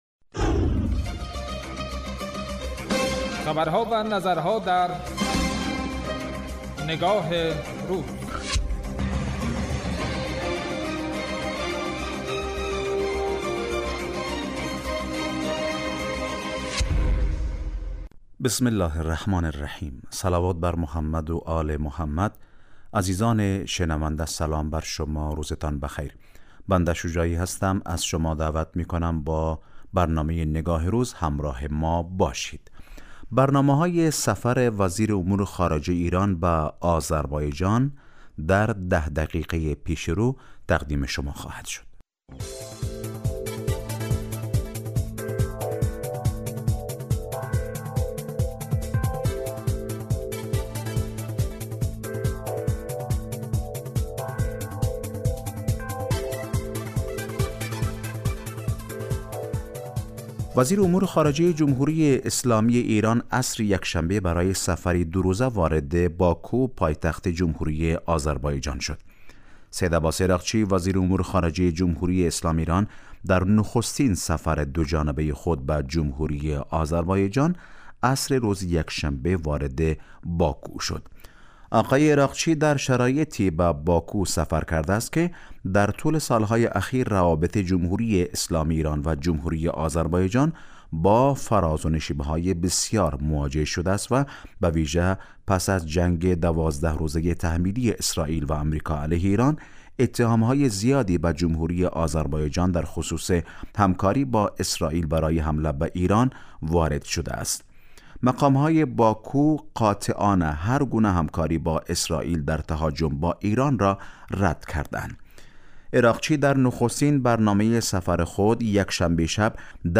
برنامه تحلیلی